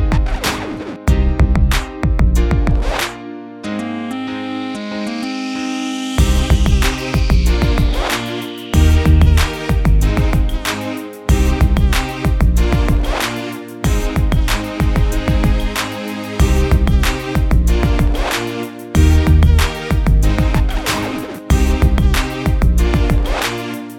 no Backing Vocals R'n'B / Hip Hop 3:45 Buy £1.50